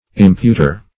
imputer - definition of imputer - synonyms, pronunciation, spelling from Free Dictionary Search Result for " imputer" : The Collaborative International Dictionary of English v.0.48: Imputer \Im*put"er\, n. One who imputes.
imputer.mp3